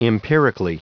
Prononciation du mot empirically en anglais (fichier audio)
Prononciation du mot : empirically